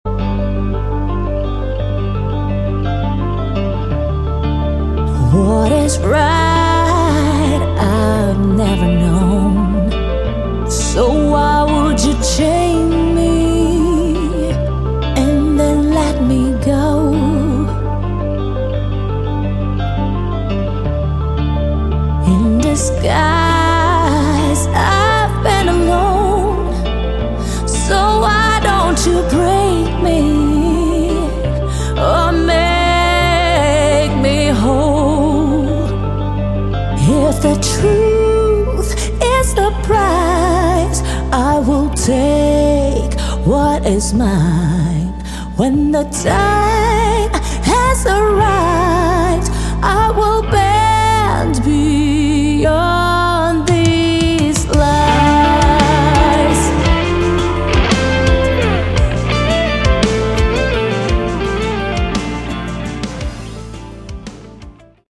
Category: Melodic Metal
vocals
guitars, bass, synths/electronic arrangements
drums